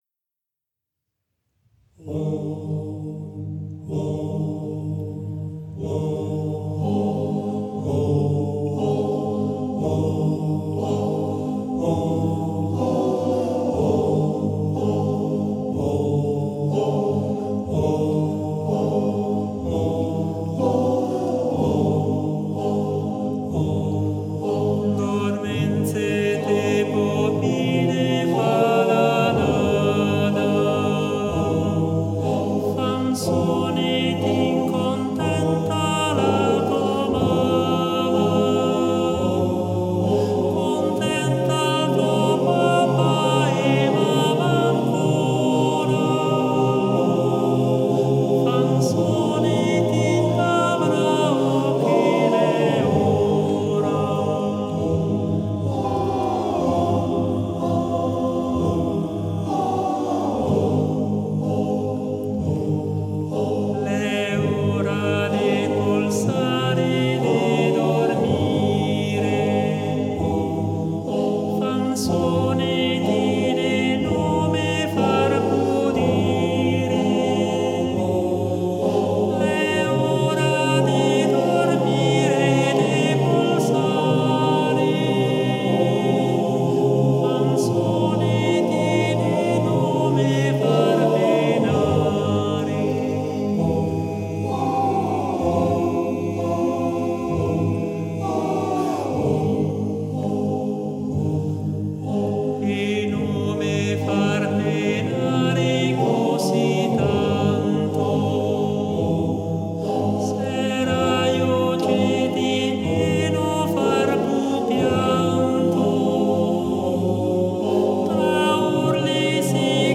Patrimoni musicali della cultura alpina
Arrangiatore: Benedetti Michelangeli, Arturo
Esecutore: Coro della SAT